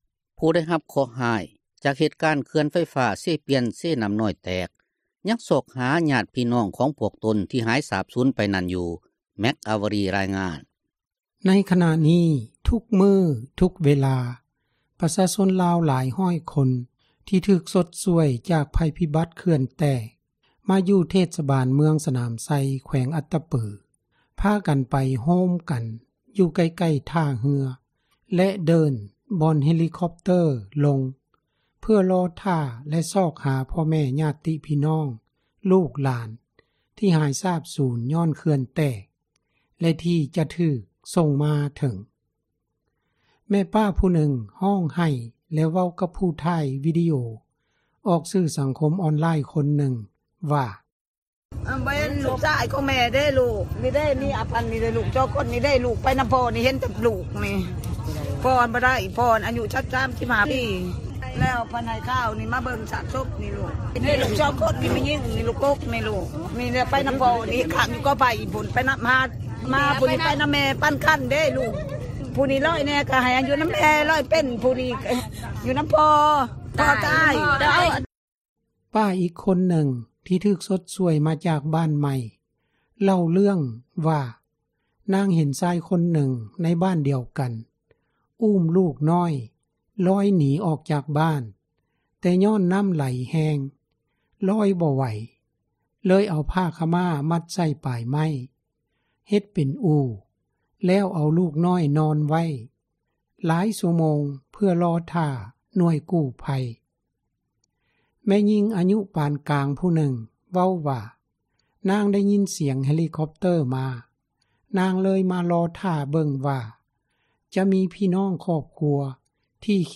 ແມ່ປ້າຜູ້ນຶ່ງຮ້ອງໃຫ້ແລະເວົ້າກັບຜູ້ຖ່າຍວີດີໂອອອກສື່ສັງຄົມອອນລາຍຄົນນຶ່ງວ່າ